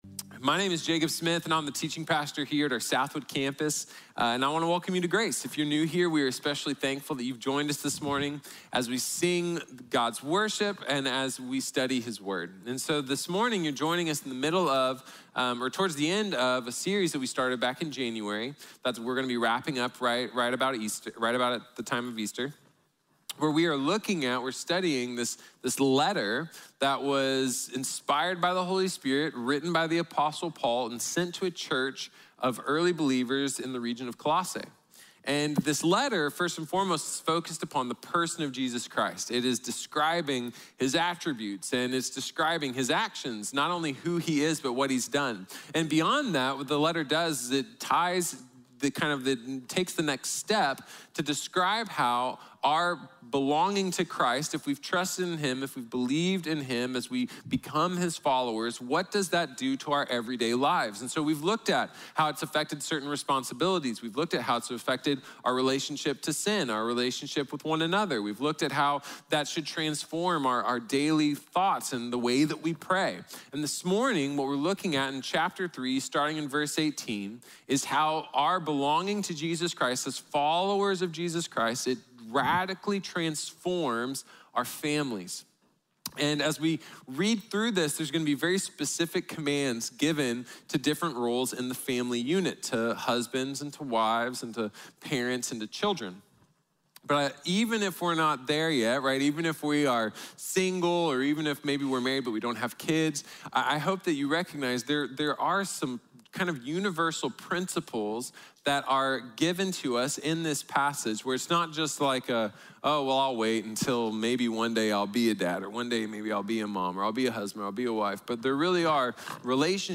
Complete Families | Sermon | Grace Bible Church